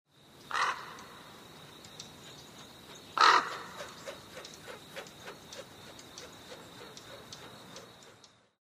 Звуки воронов
На этой странице собраны разнообразные звуки воронов – от резкого карканья до глухого клекота.